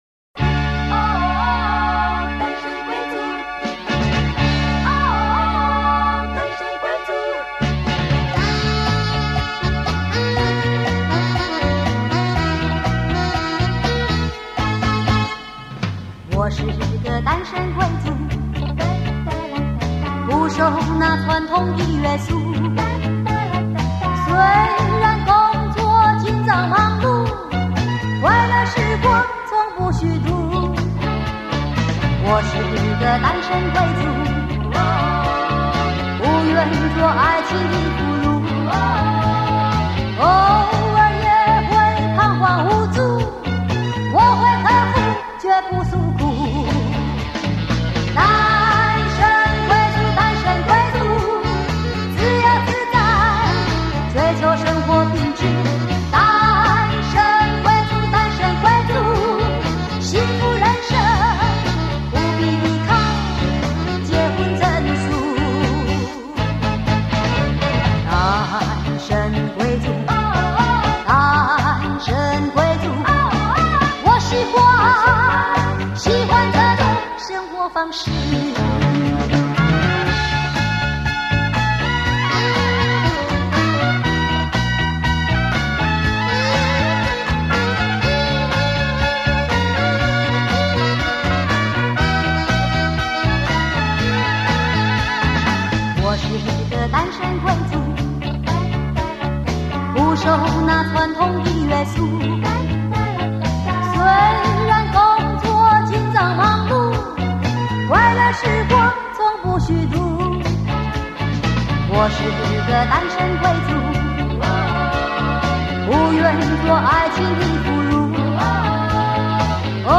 最后几首就带有很浓的"凤味".